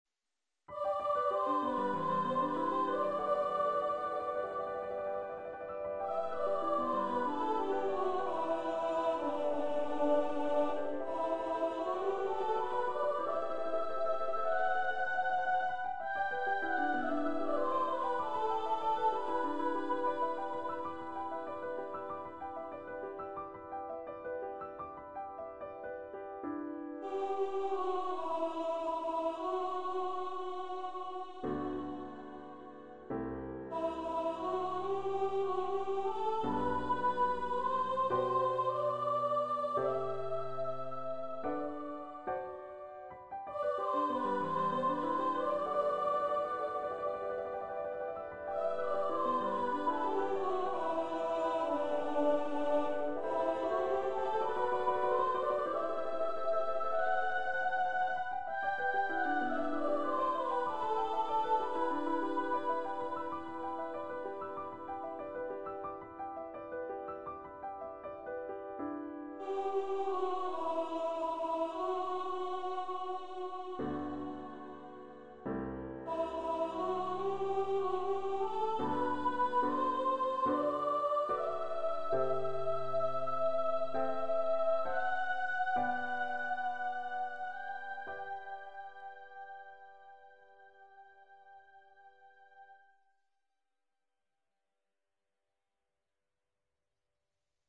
Voice, Piano
Composer's Demo